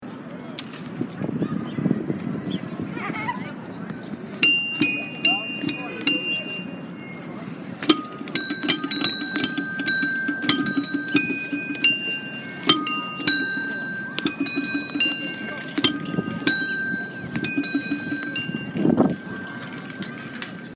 In New York, I like taking people to Hester Street Playground in Chinatown / the Lower East Side.
Stepping on each square produces a ring or bell sound. Each square is a different note.
golden DDR pad,
golden-sounds.mp3